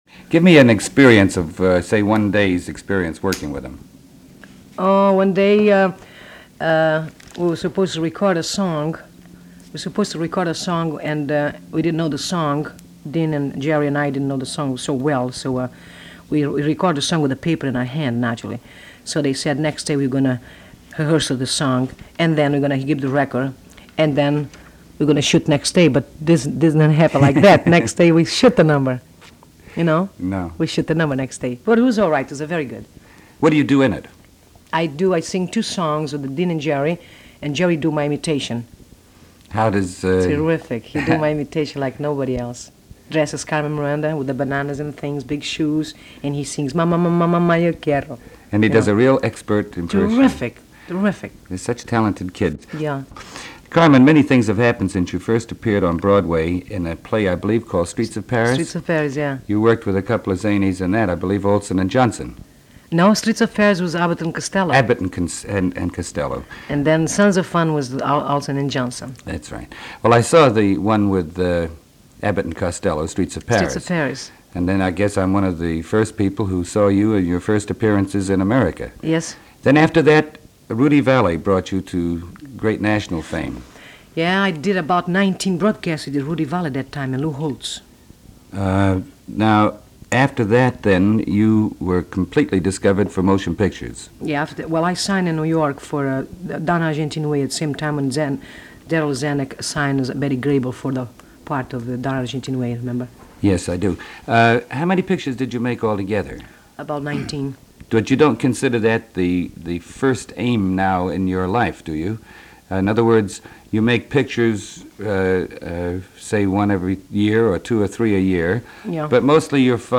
The raw interview was intended to be cut up, with portions used for his daily radio show. She is being interviewed in connection with completion of shooting her latest film Scared Stiff, starring Dean Martin and Jerry Lewis, which would be released in 1953.
Carmen-Miranda-Interview-July-1952.mp3